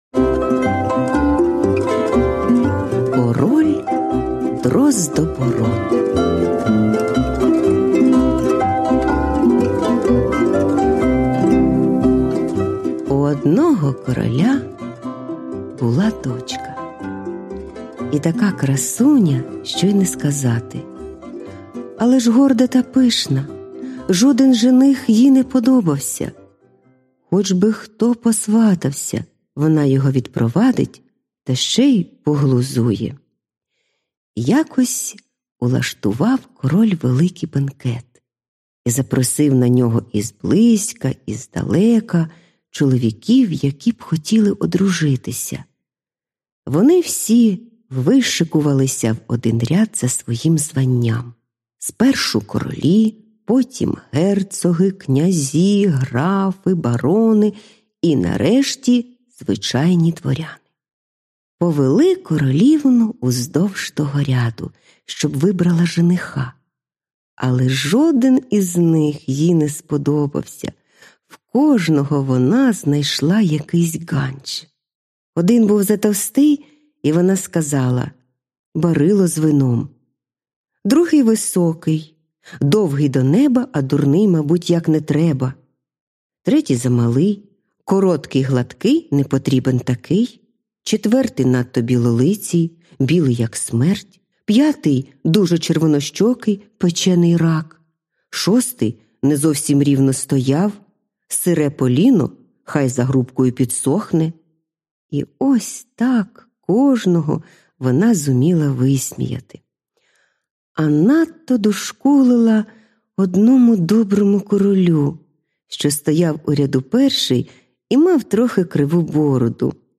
Аудіоказка Король Дроздобород